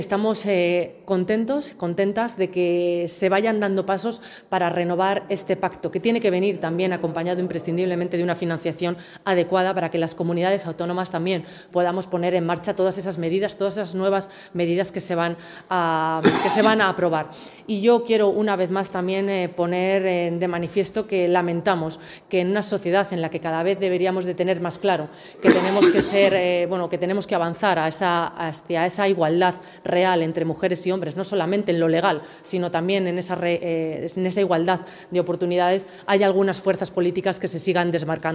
Consejería de Igualdad Lunes, 17 Febrero 2025 - 1:15pm Preguntada por los medios, Simón ha valorado positivamente la renovación del Pacto de Estado contra la Violencia de Género anunciada para este lunes.